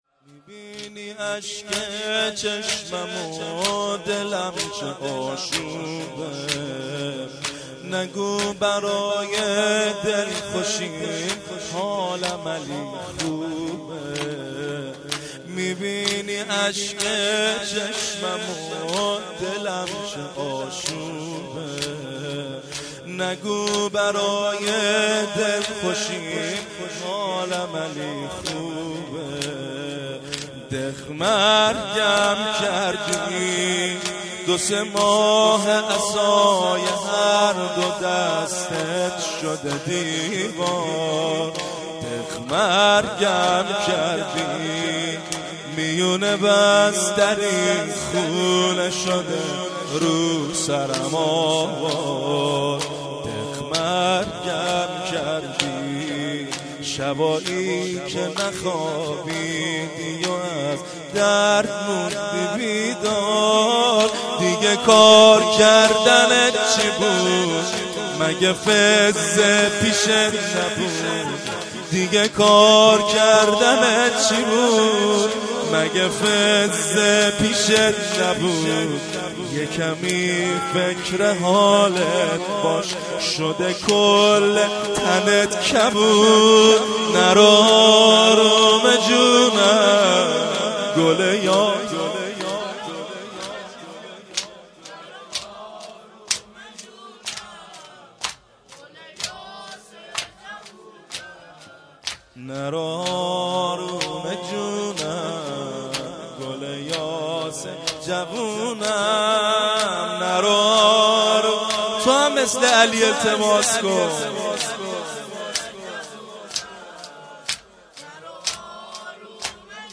توسل هفتگی-روضه حضرت زهرا(س)-27 بهمن 1396